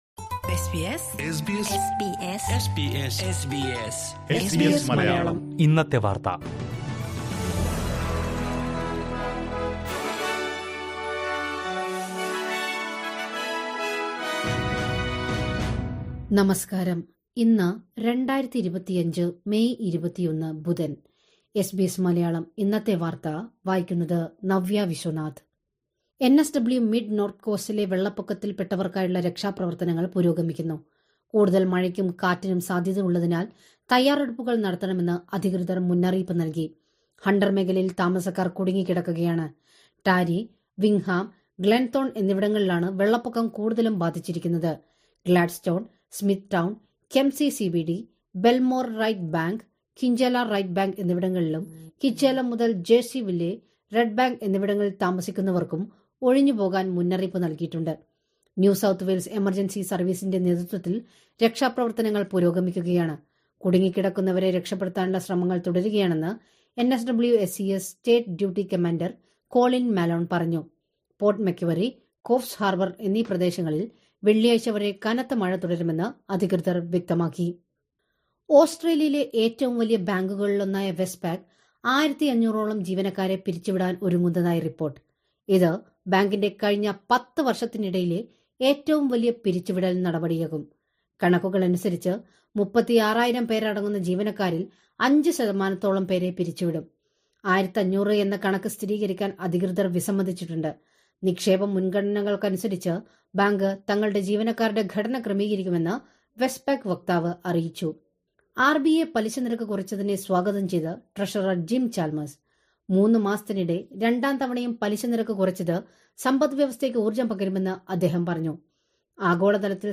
2025 മേയ് 21ലെ ഓസ്‌ട്രേലിയയിലെ ഏറ്റവും പ്രധാന വാര്‍ത്തകള്‍ കേള്‍ക്കാം...